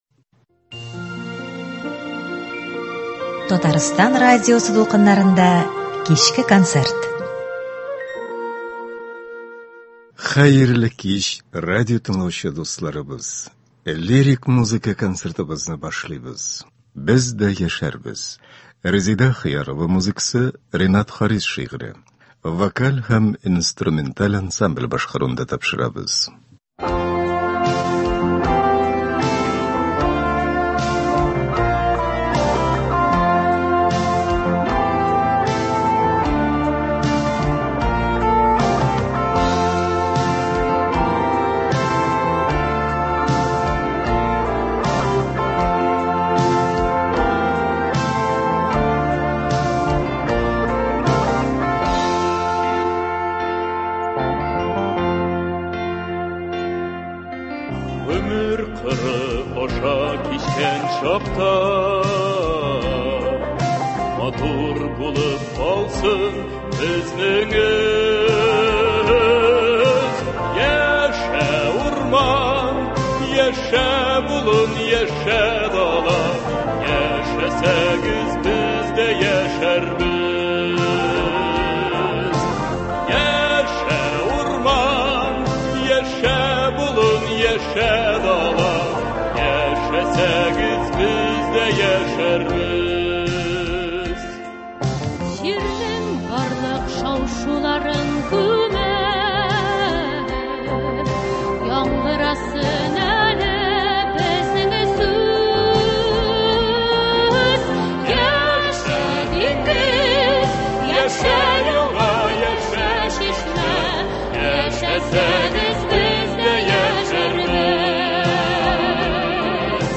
Лирик музыка концерты.